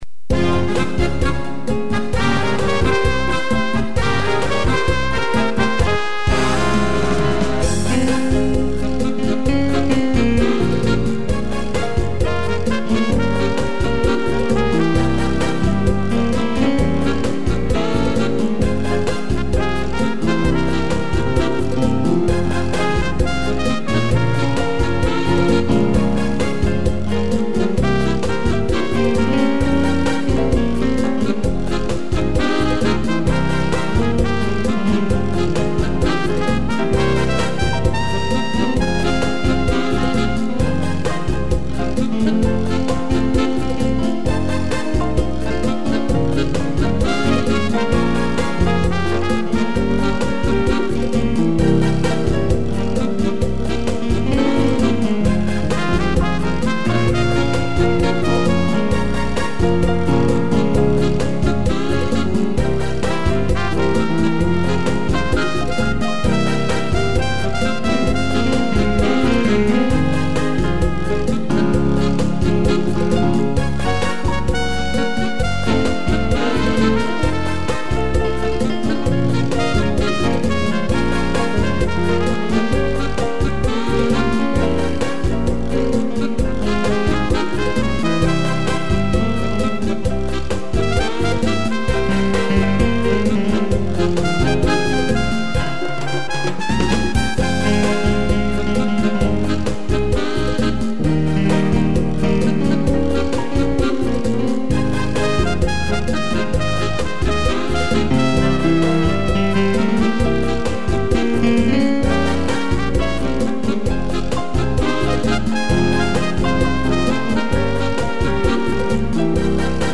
piano e trompete
(instrumental)